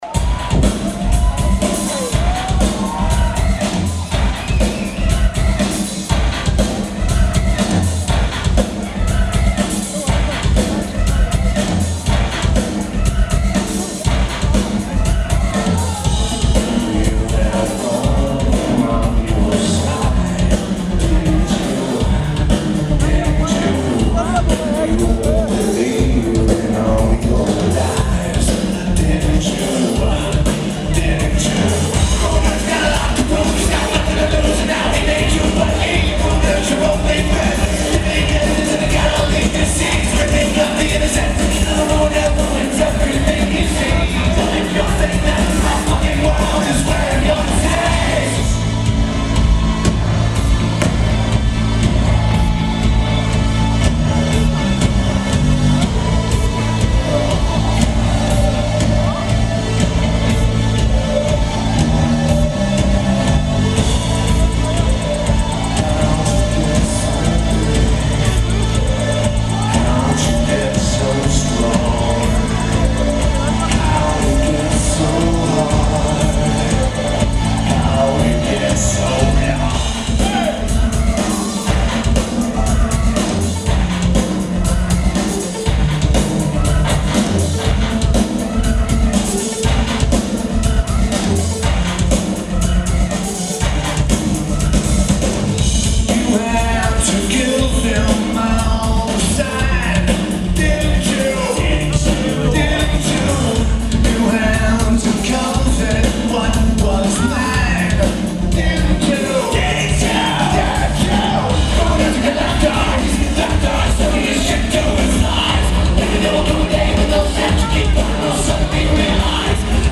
Carling Academy (Makeup from 03/05)
Birmingham, England United Kingdom
Lineage: Audio - AUD (Sony ECM-717 + Sony MZR-35)
Clear vocals and not much in terms of crowd noise.